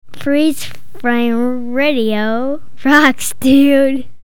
Bumper 2